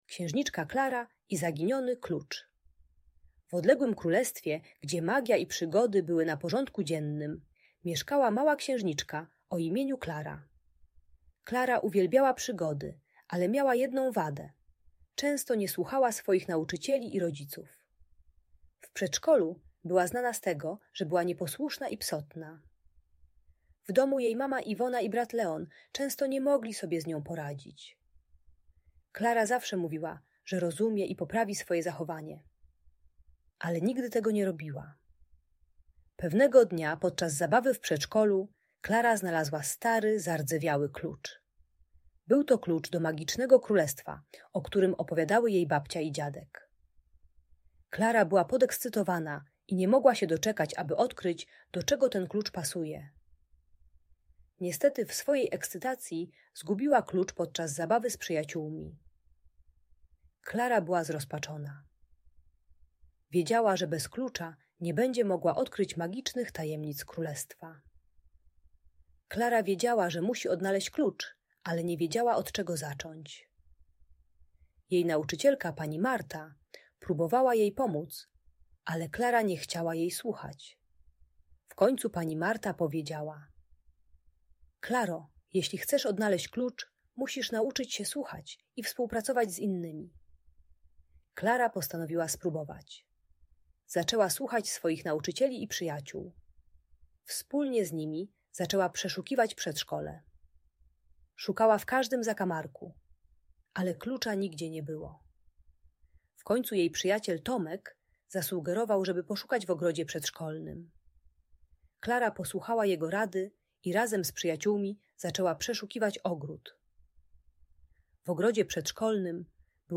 Księżniczka Klara i Zaginiony Klucz - Bunt i wybuchy złości | Audiobajka